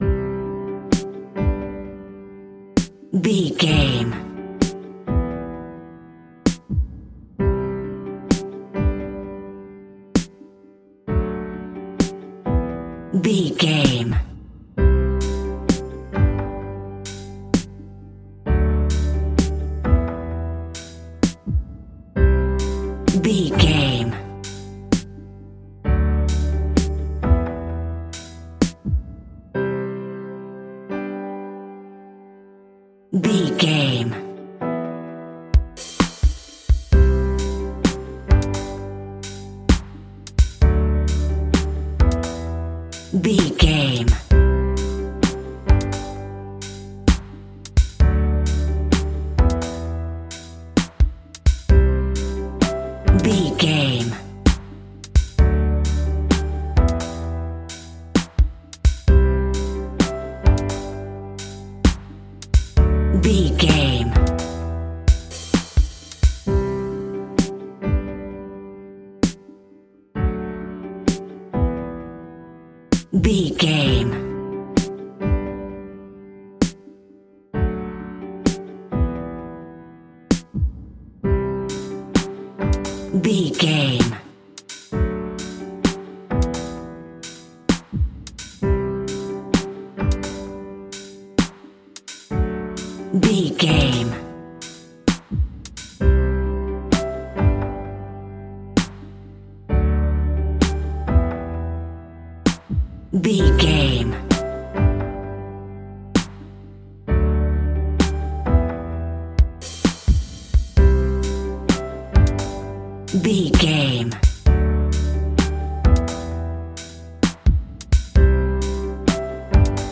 Ionian/Major
Slow
hip hop
chilled
laid back
hip hop drums
hip hop synths
piano
hip hop pads